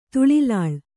♪ tuḷilāḷ